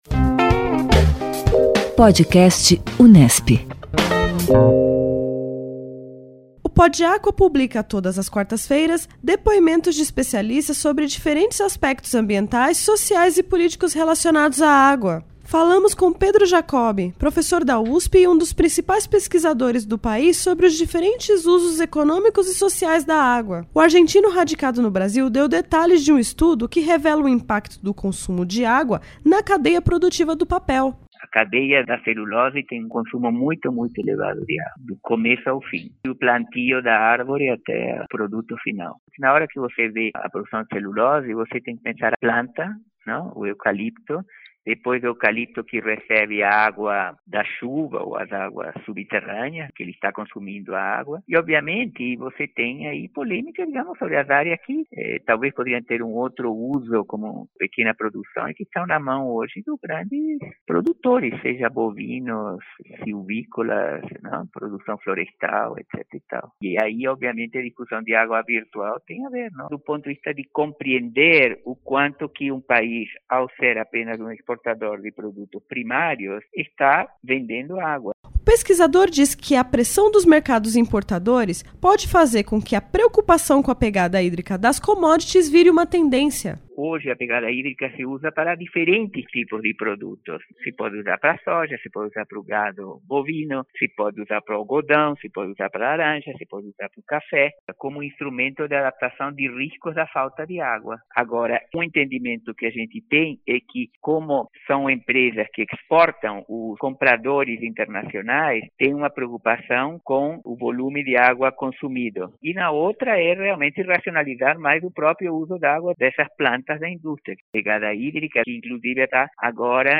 O PodAcqua traz trechos de entrevistas com especialistas da Unesp e de outras instituições, nas mais diferentes áreas do conhecimento, com atenção especialmente voltada à gestão responsável dos recursos hídricos.